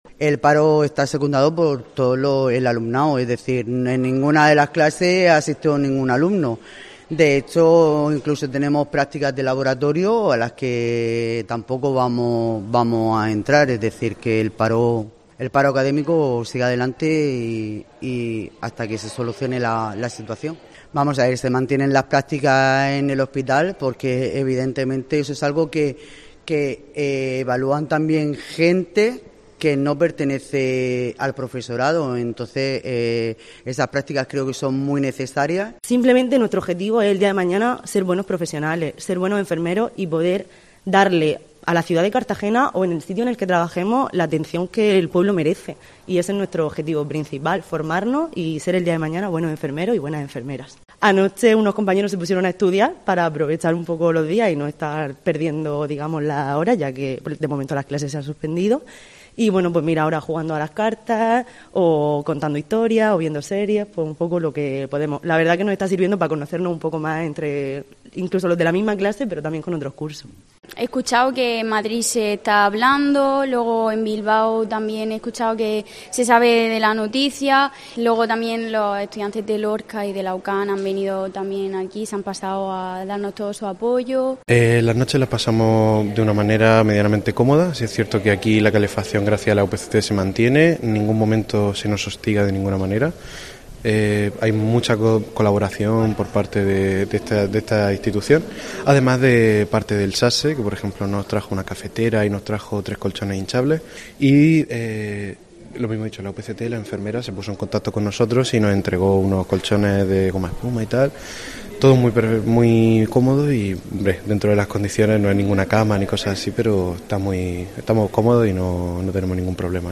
Hoy, rodeados de colchones y mochilas, han explicado en Cope sus reivindicaciones y lo que hacen para matar el tiempo durante las 24 horas.